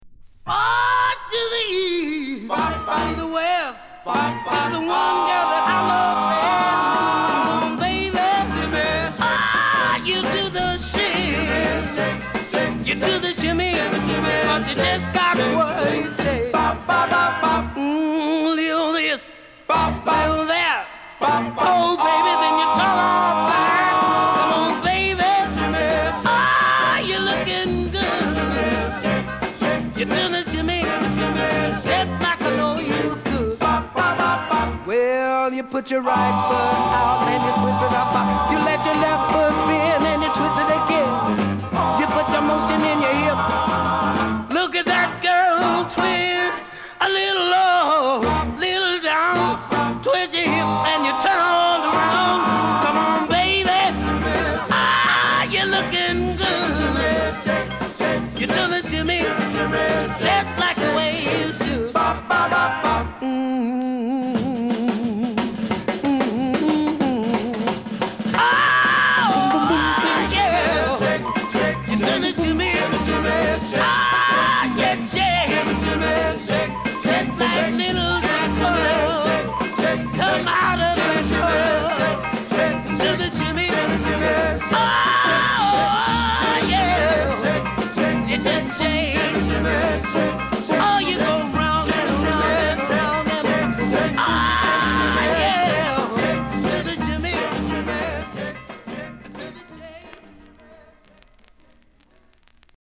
アーリー60'sのダンス・ブームに感化された、ホットで激しいR&Bサウンドを響かせる。